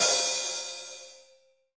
Index of /90_sSampleCDs/Sound & Vision - Gigapack I CD 1 (Roland)/KIT_REAL m 9-12/KIT_Real-Kit m11
HH HH289.wav